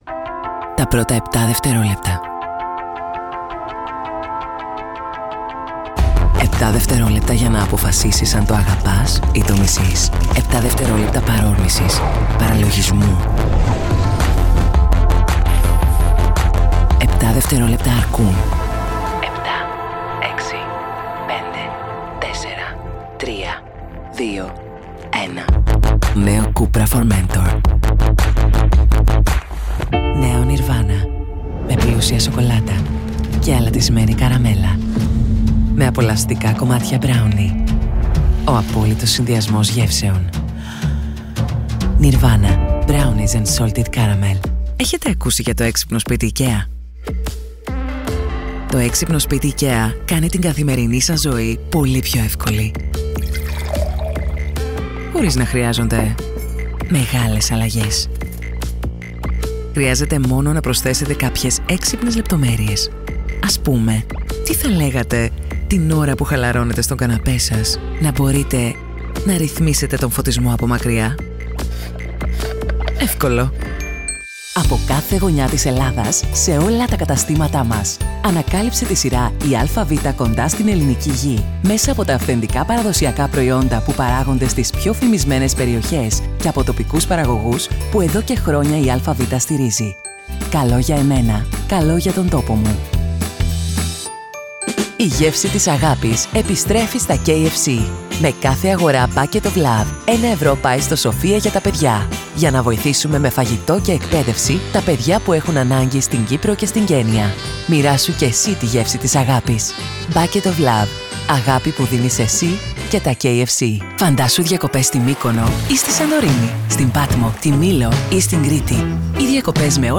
Female
Approachable, Assured, Authoritative, Character, Children, Confident, Conversational, Corporate, Deep, Energetic, Engaging, Friendly, Natural, Reassuring, Smooth, Versatile, Warm
Microphone: MKH 416 Sennheiser Shotgun
Audio equipment: Professionally sound-proofed home studio room, RME Fireface UCX II, Audio-Technica ATH M50x Headphones, Kali Audio studio monitors